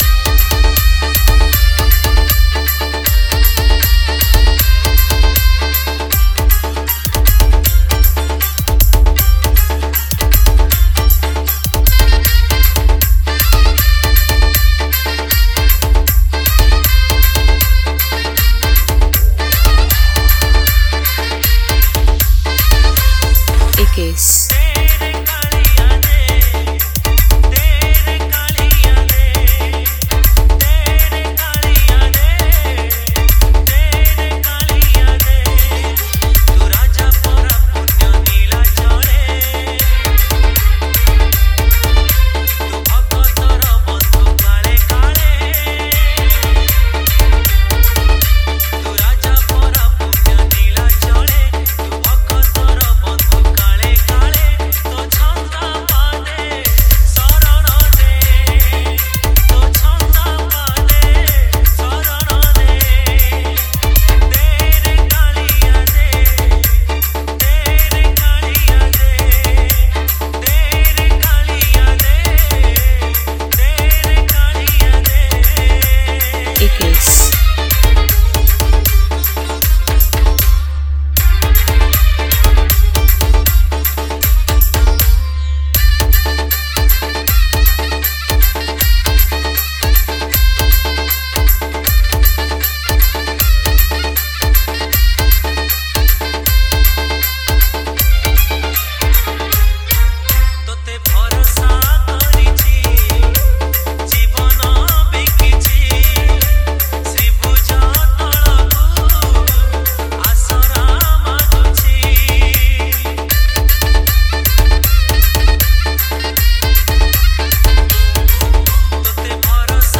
Bhajan Dj Song